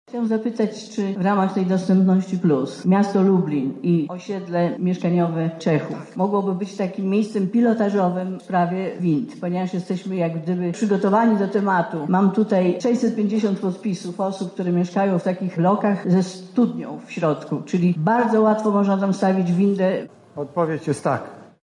Po przemówieniu szefa rządu przyszedł czas na pytania lublinian. Dotyczyły one przeważnie spraw lokalnych, ale poruszone zostały również sprawy ważne dla ogółu społeczeństwa: